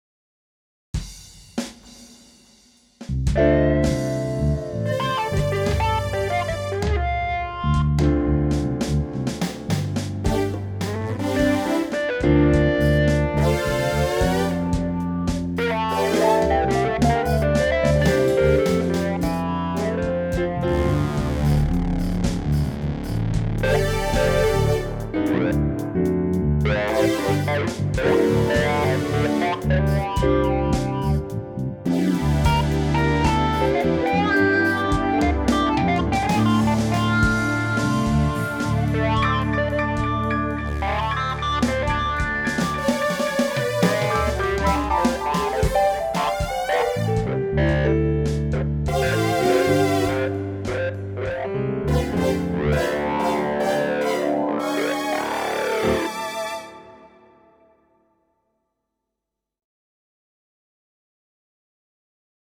stereo mp3 float, 320kb/s 1min2s, 2.4 MB, about -3dBr Max, but wide range and loud
After that straight to the ffmpeg mp3 recorder, no processing no software effects or instruments, and no editing.